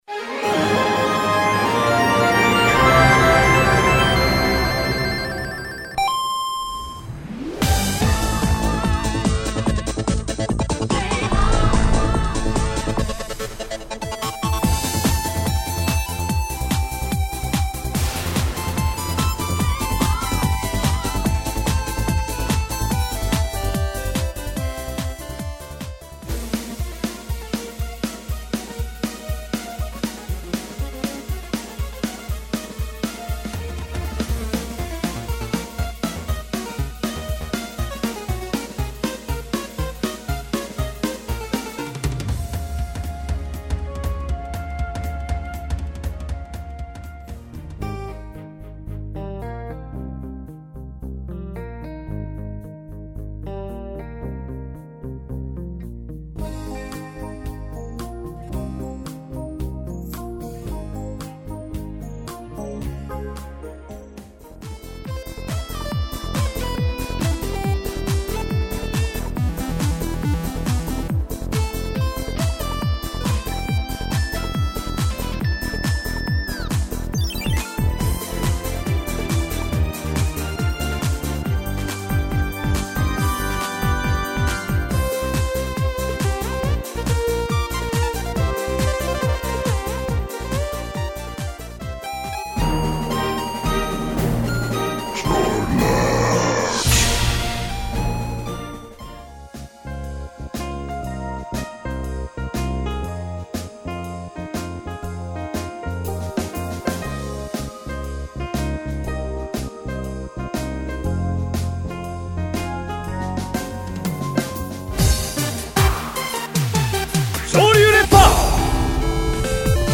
Een compilatie van muziek en geluiden